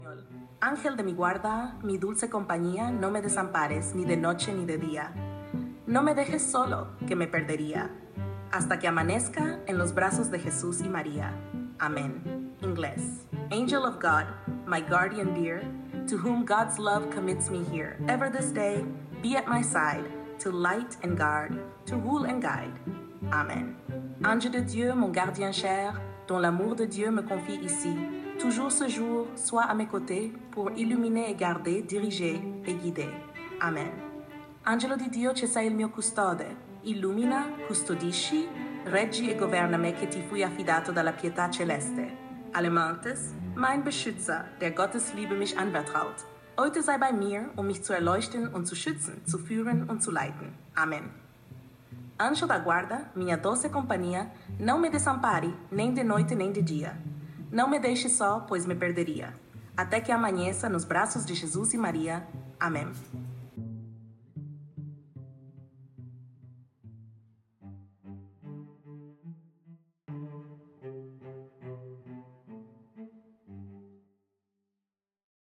Oración a tu Ángelito de la Guarda
por Mujer
Angelito-de-mi-guarda-MUJER.mp3